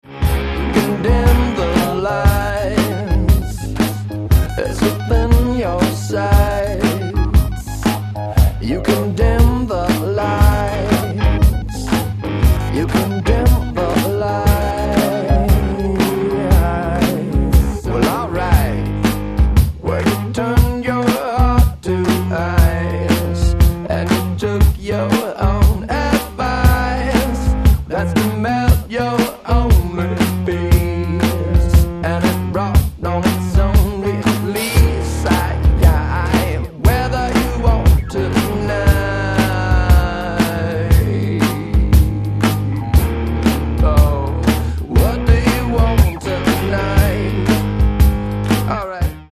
superhot psychedelic funk from outta space